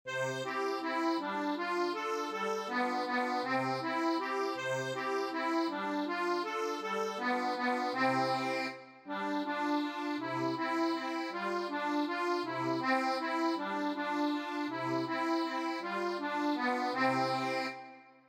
Alles spielt sich auf der C-Reihe ab. Die linke Hand wiederholt stereotyp die gleiche Begleitung auf den gleichen Tasten. Und die einfach gehaltene Melodie lässt sich mit drei Fingern spielen und besteht fast nur aus Viertelnoten, die zeitgleich mit der Begleitung erklingen, Balgwechsel immer nur am Taktanfang.
Speziell für Deutsche CG-Konzertina: Tabulatur: (62 KB) (340 KB)